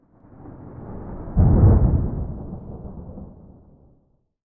thunderfar_3.ogg